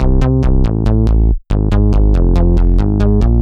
Index of /musicradar/french-house-chillout-samples/140bpm/Instruments
FHC_MunchBass_140-C.wav